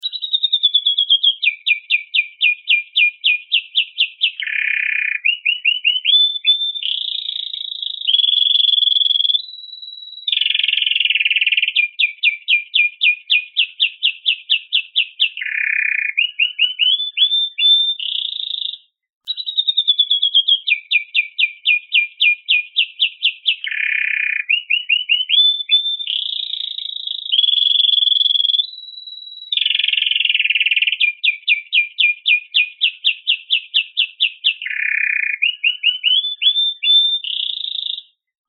カナリアの鳴き声は、その美しさと特徴的な魅力で知られています。